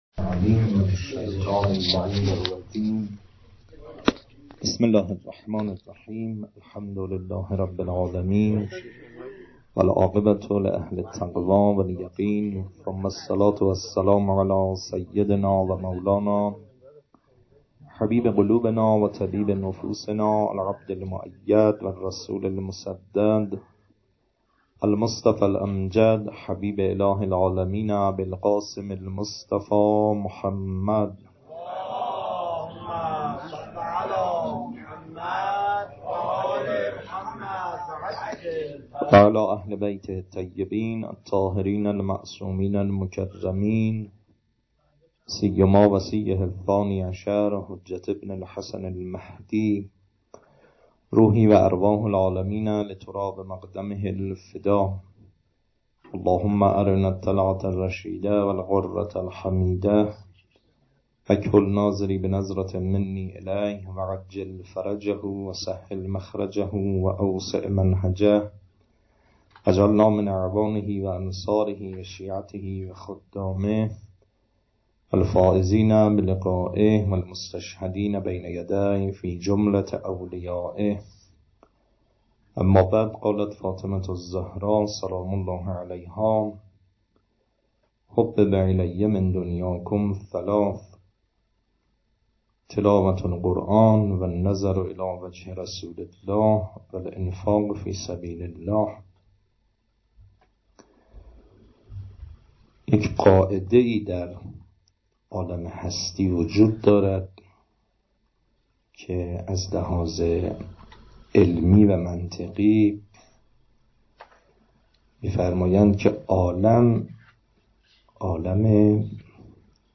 در مراسمی به مناسبت ایام فاطمیه(س)